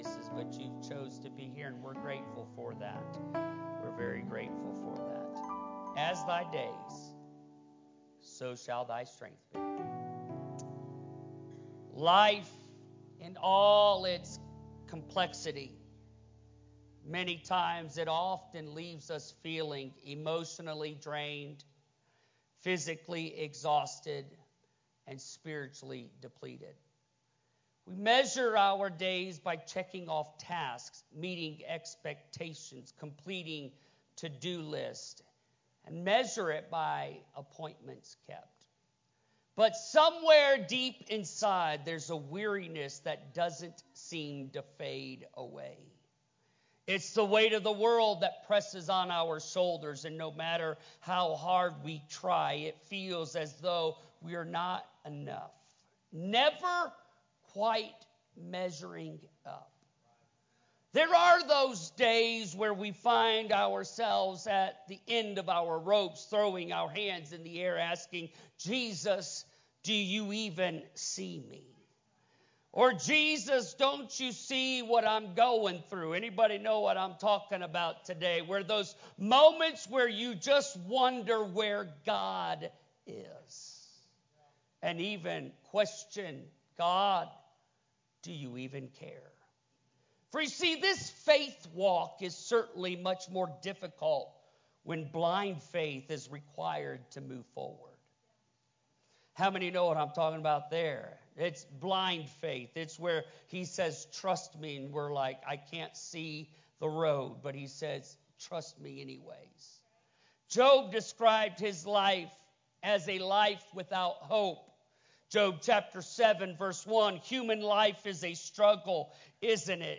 Sunday-sermon-CD.mp3